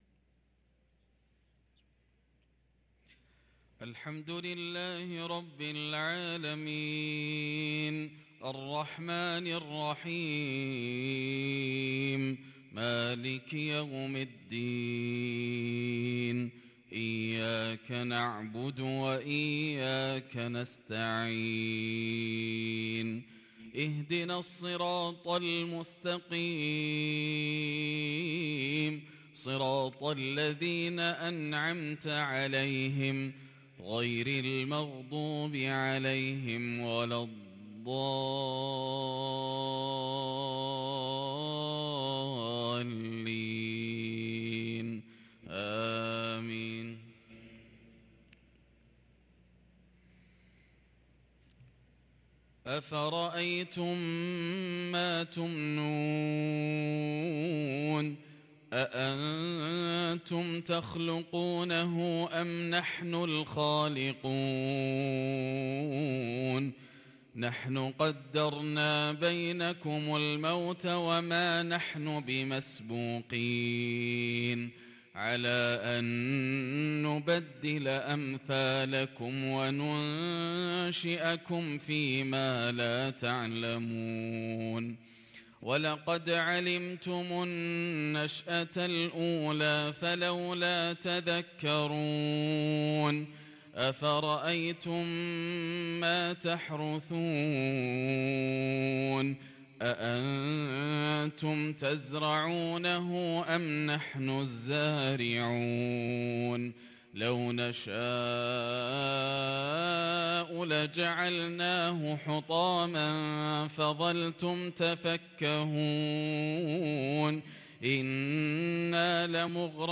صلاة العشاء للقارئ ياسر الدوسري 4 صفر 1444 هـ